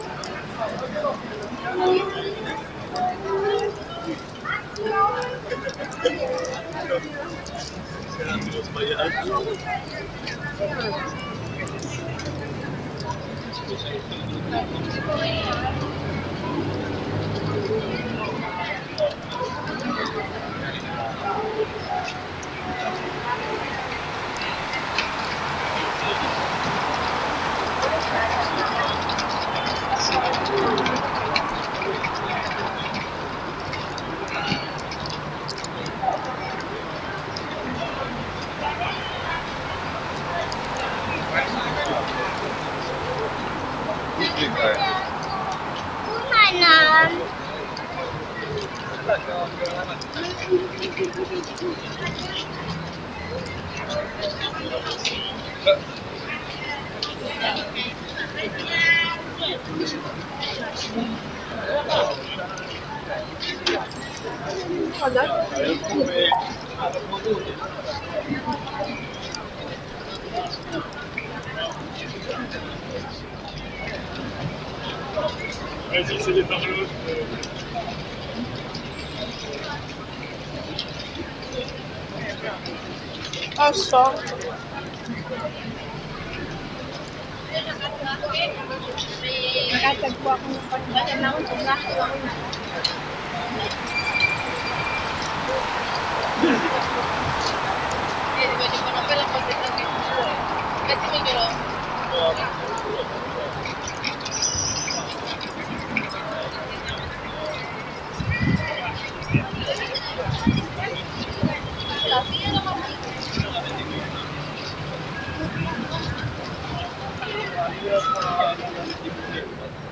oa-italia-napoles-via-toledo.mp3